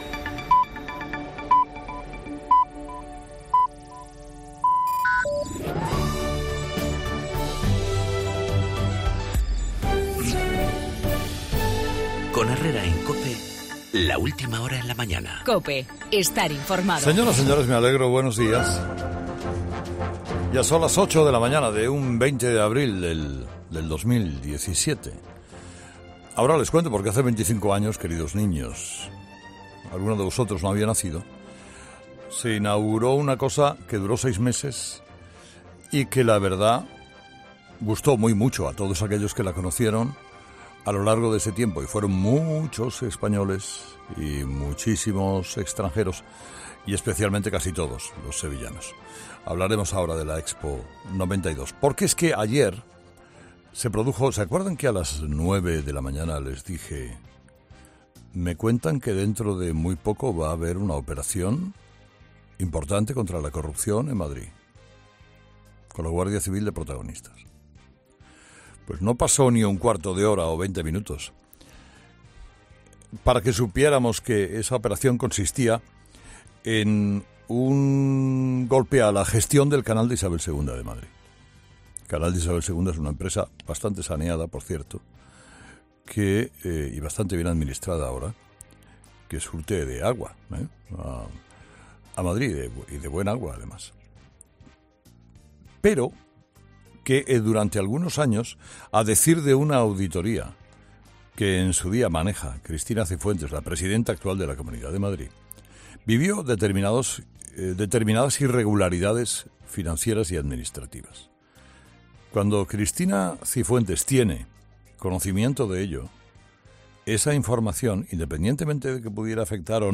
AUDIO: La operación Lezo con la detención de Ignacio González y los 25 años de la Expo de Sevilla, en el monólogo de Carlos Herrera a las 8 de la mañana.